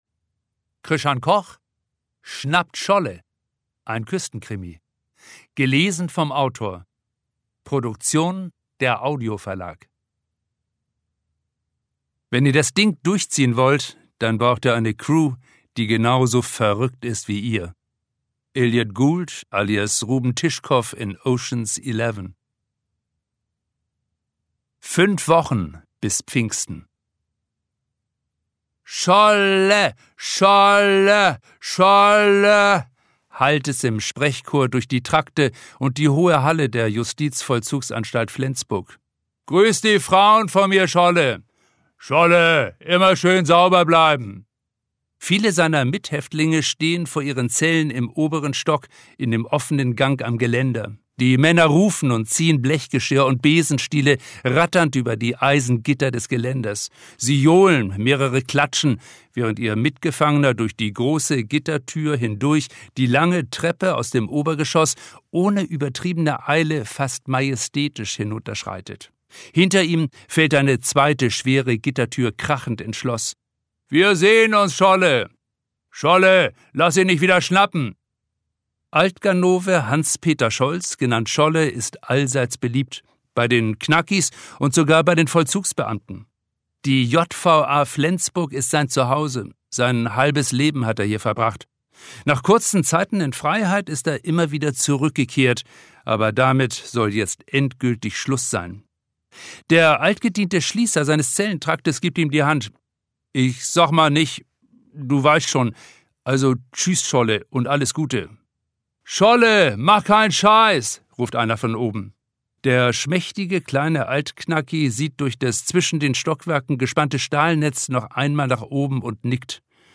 Schlagworte Autorenlesung • Fredenbüll • Hidde Kist • Humor • Krimi • Neuerscheinung 2023 • Nicole Stappenbek • Norden • Piet Paulsen • Regionalkrimi • Thies Detlefsen